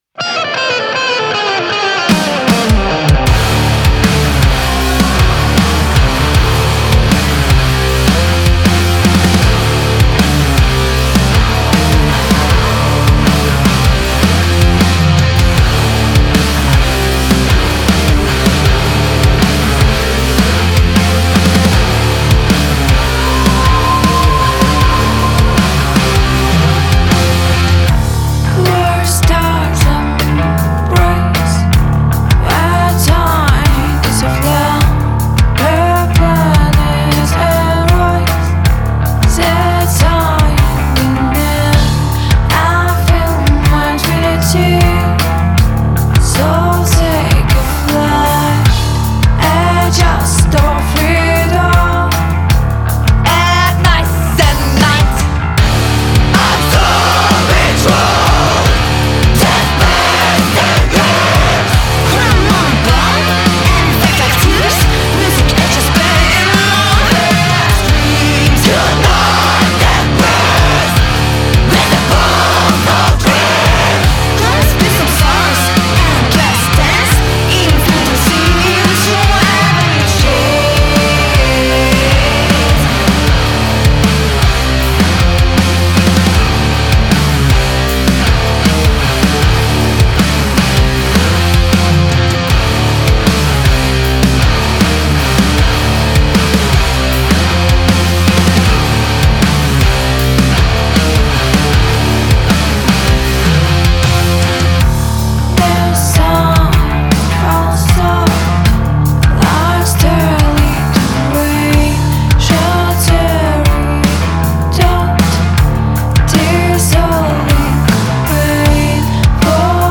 prog metal stoner